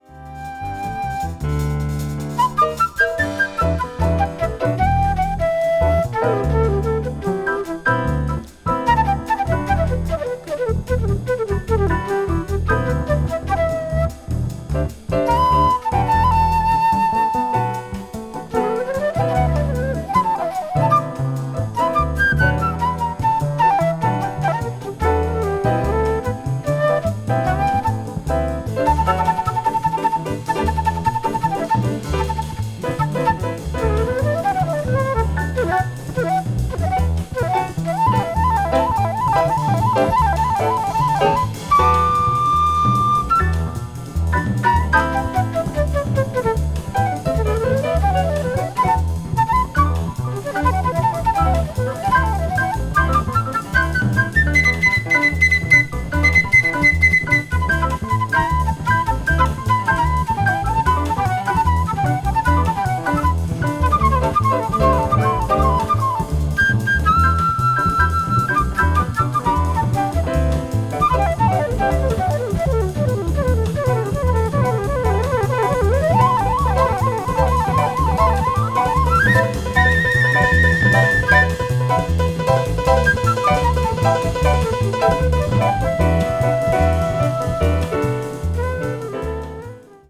media : EX-/EX(わずかにチリノイズが入る箇所あり,A3:再生音に影響ない薄く細かいスリキズあり)